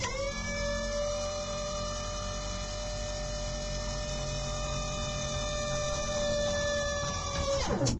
GunLower1.ogg